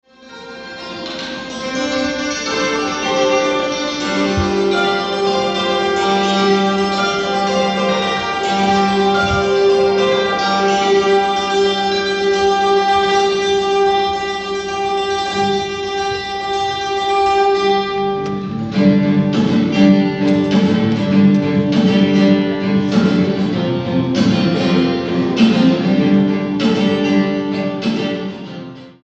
STYLE: Roots/Acoustic
Live